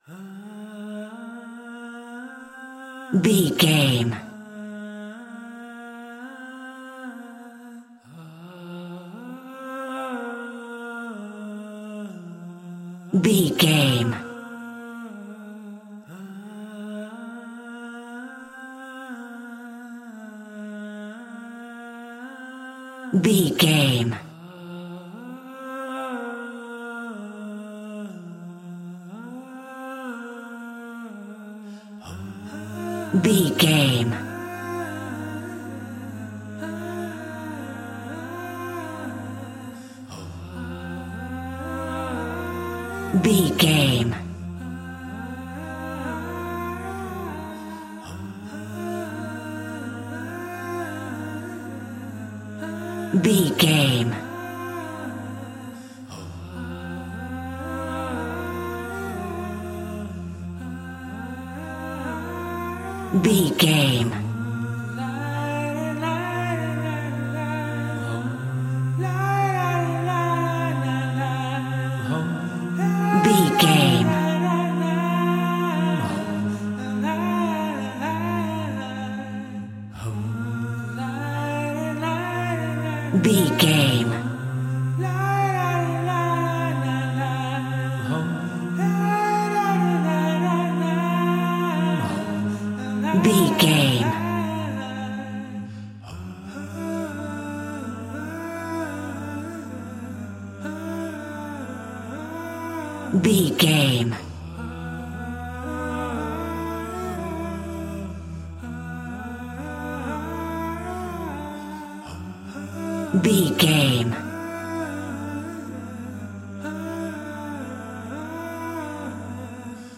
Aeolian/Minor
groovy
inspirational